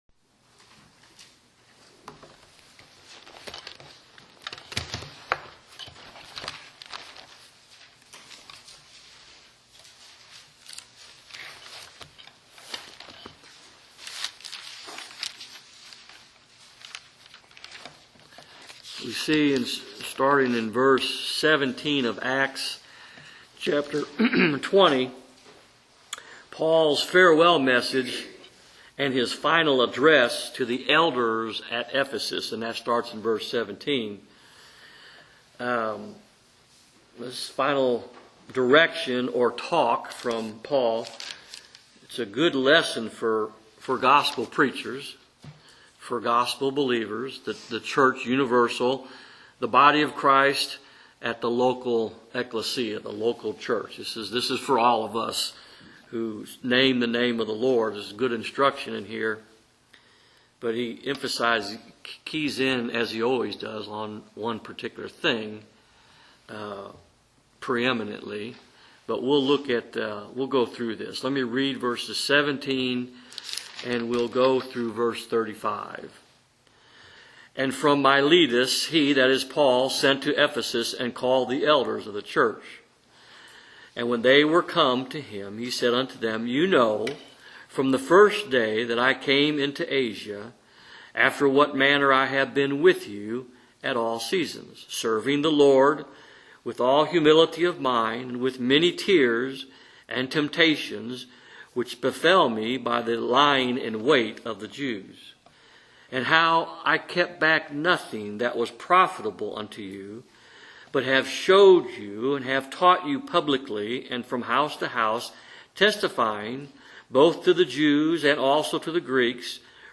The Gospel of the Grace of God | SermonAudio Broadcaster is Live View the Live Stream Share this sermon Disabled by adblocker Copy URL Copied!